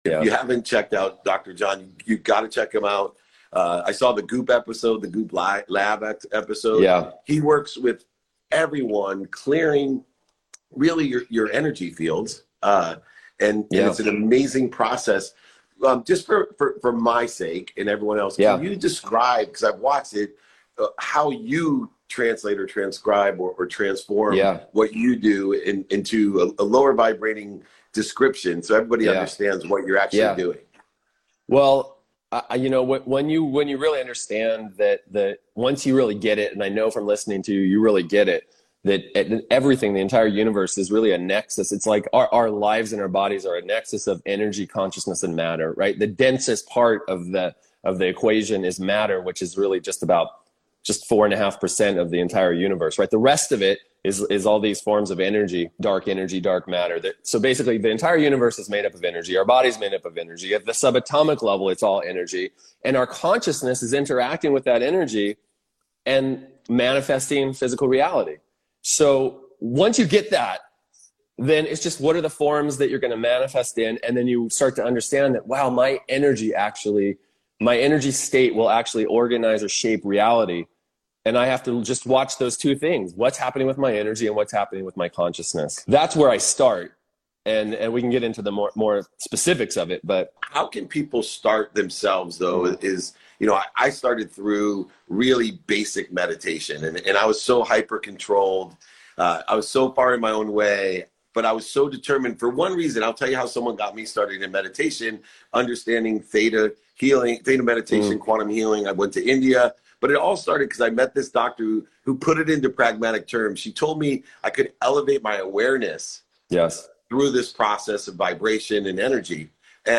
Today's episode is from a conversation